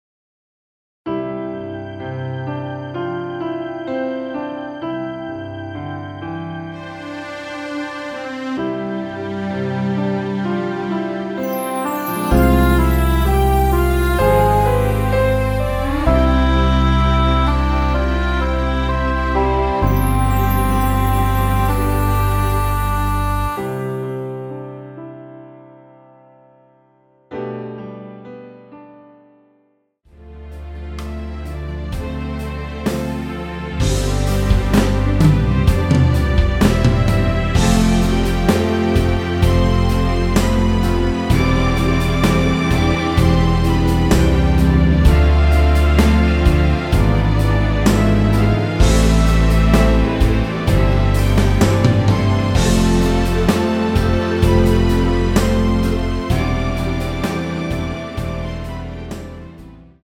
앞부분30초, 뒷부분30초씩 편집해서 올려 드리고 있습니다.
곡명 옆 (-1)은 반음 내림, (+1)은 반음 올림 입니다.